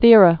(thîrə, thērä) Formerly San·to·ri·ni (săntə-rēnē)